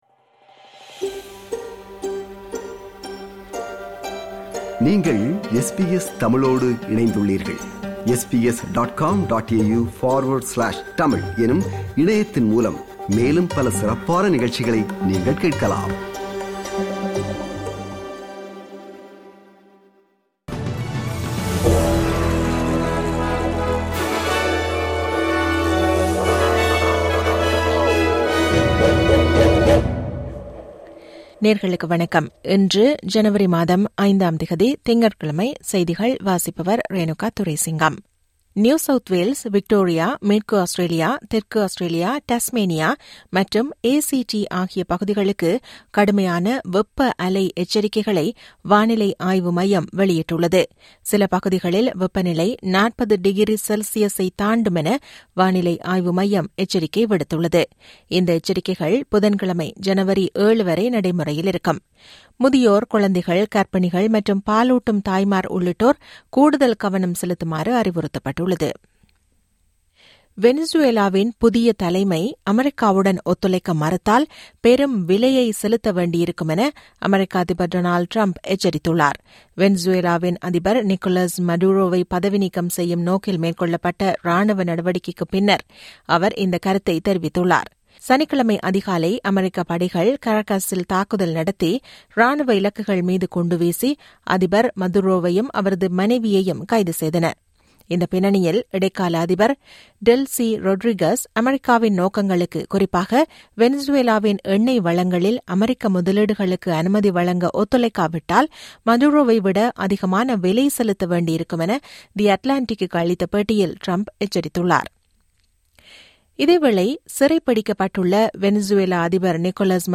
இன்றைய செய்திகள்: 05 ஜனவரி 2026 - திங்கட்கிழமை
SBS தமிழ் ஒலிபரப்பின் இன்றைய (திங்கட்கிழமை 05/01/2026) செய்திகள்.